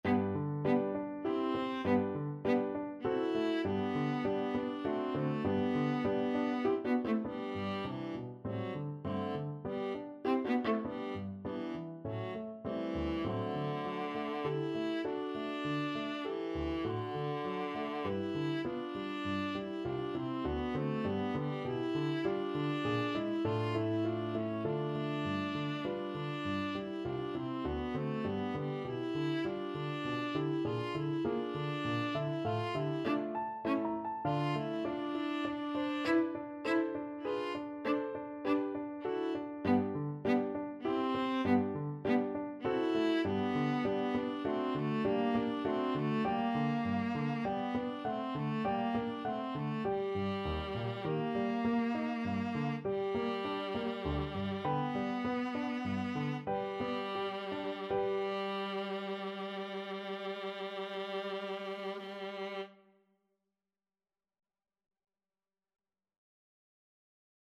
Viola
3/4 (View more 3/4 Music)
D4-F5
G major (Sounding Pitch) (View more G major Music for Viola )
~ = 100 Allegretto grazioso (quasi Andantino) (View more music marked Andantino)
Classical (View more Classical Viola Music)
brahms_sym2_3rd_mvt_VLA.mp3